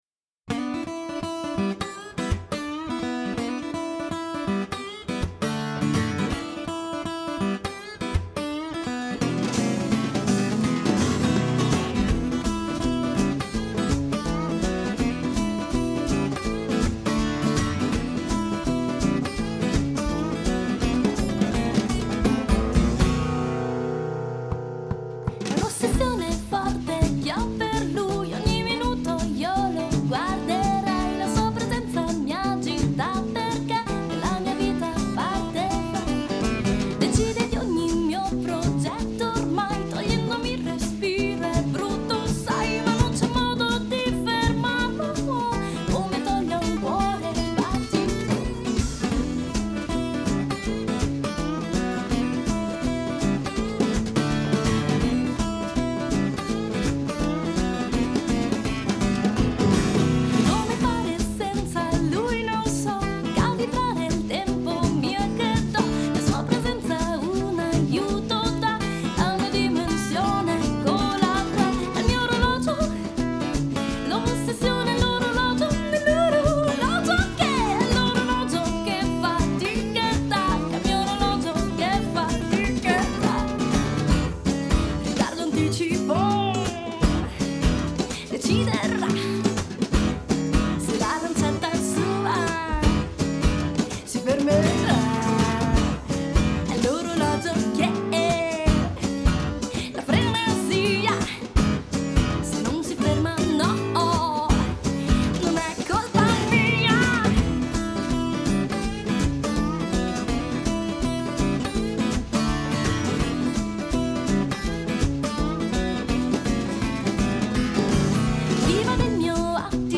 batteria